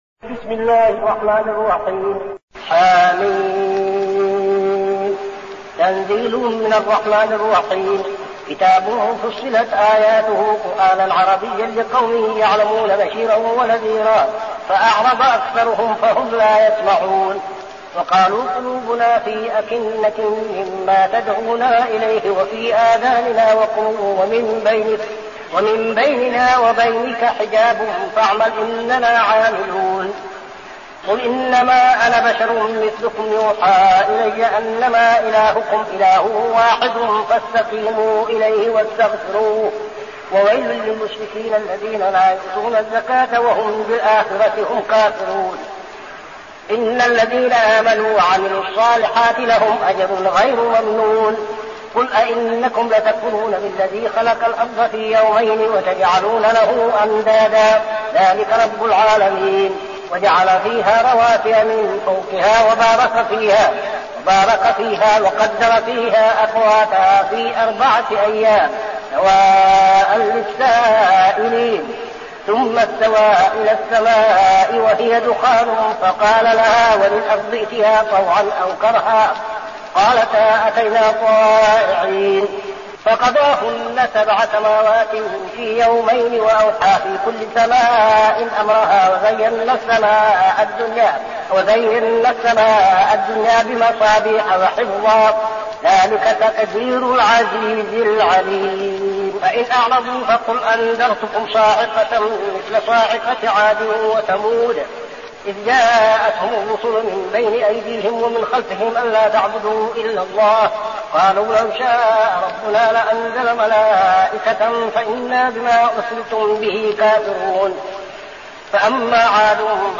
المكان: المسجد النبوي الشيخ: فضيلة الشيخ عبدالعزيز بن صالح فضيلة الشيخ عبدالعزيز بن صالح فصلت